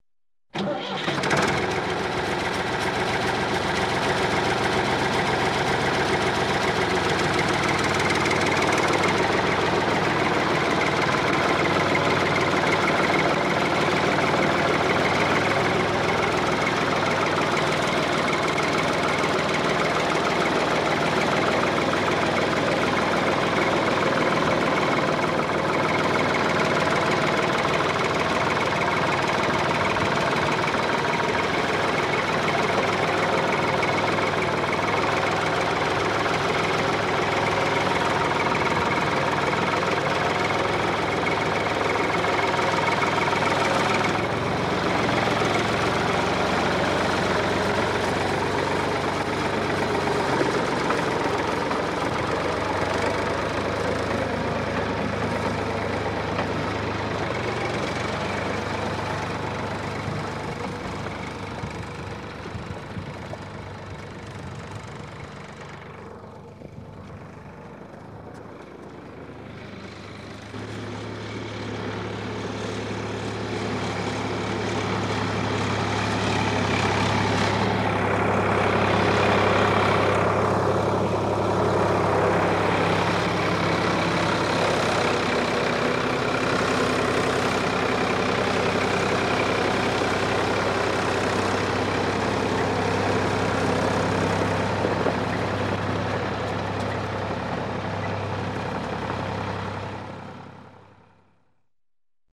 Звук трактора: пуск мотора, работа на холостом ходу, движение
Тут вы можете прослушать онлайн и скачать бесплатно аудио запись из категории «Авто, транспорт, машины».